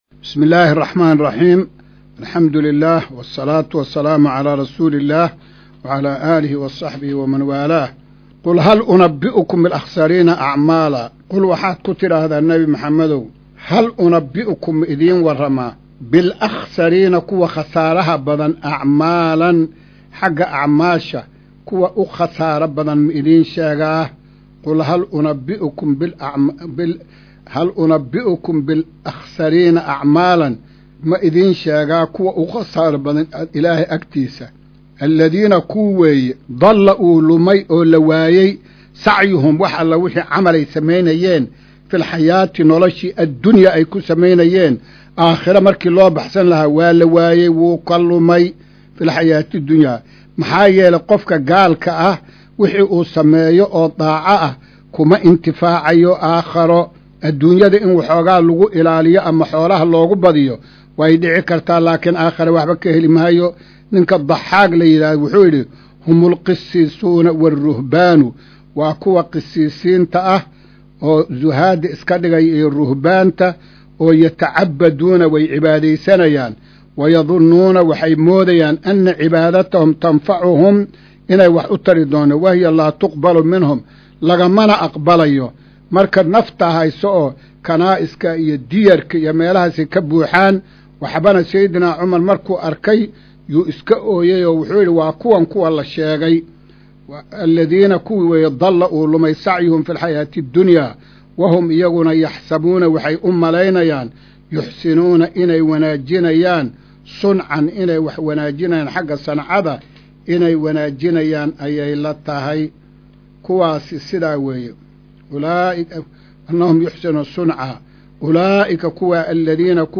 Maqal:- Casharka Tafsiirka Qur’aanka Idaacadda Himilo “Darsiga 148aad”